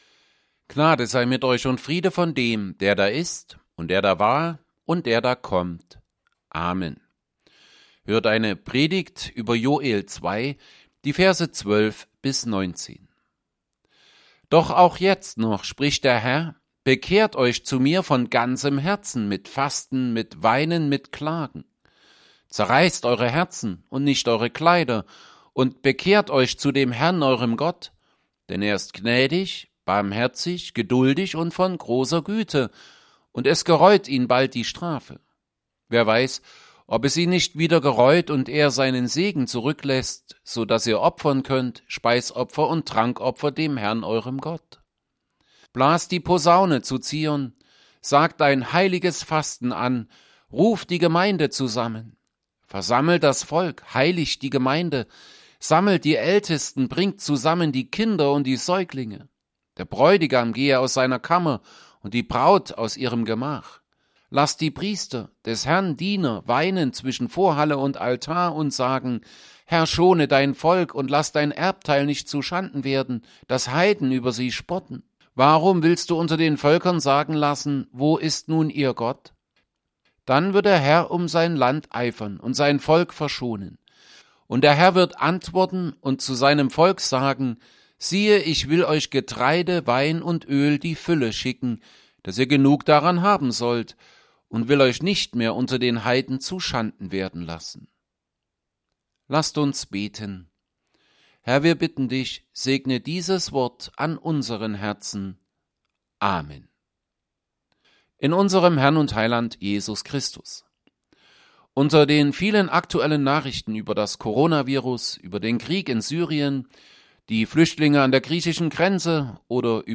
Predigt_zu_Joel_2_12b19.mp3